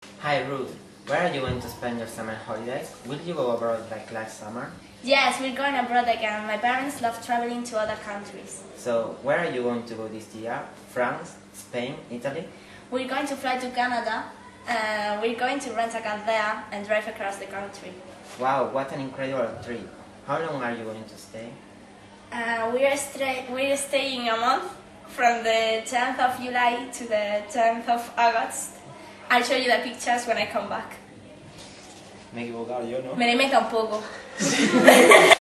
Everyday conversations
Chico y chica de pié en biblioteca mantienen una conversación